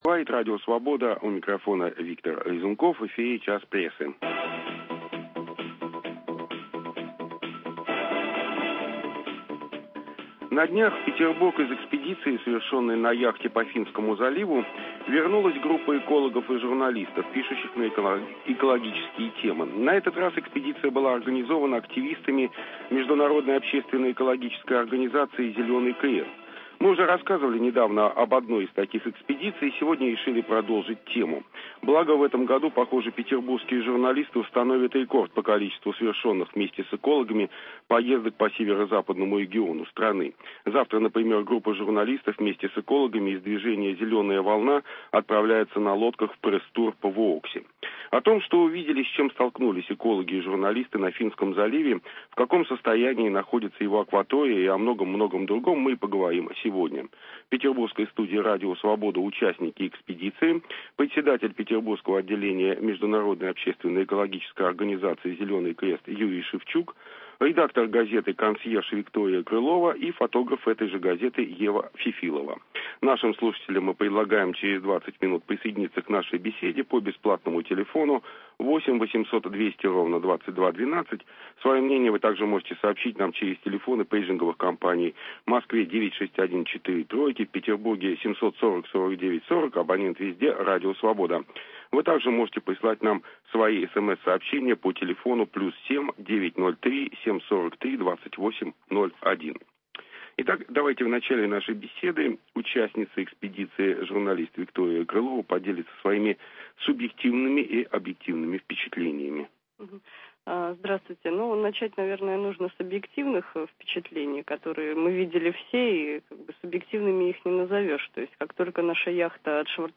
Петербургские экологи и журналисты рассказывают о результатах, только что закончившейся, экспедиции по Финскому заливу. Какая экологическая ситуация в этом регионе? Почему намыв территорий на Васильевском острове вредит Финскому заливу?